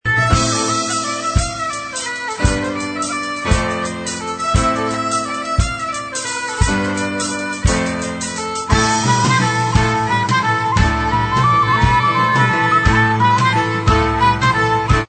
folk influences celtiques